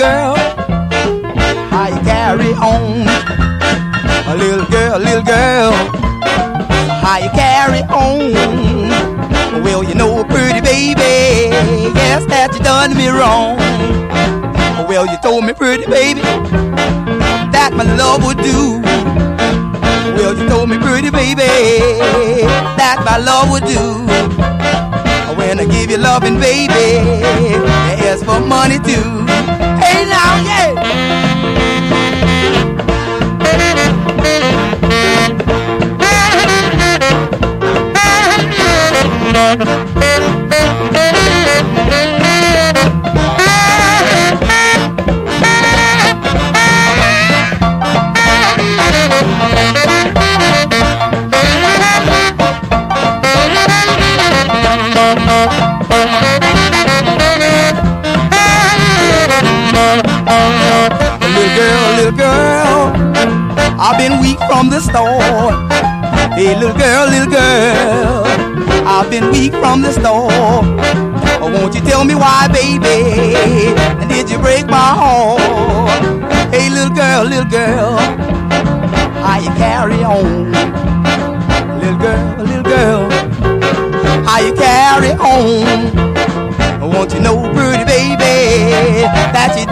SOUL / SOUL / 70'S～ / DISCO
ハウス系DJもプレイするディスコ・ブギー・クラシック～レア・ディスコを12曲収録！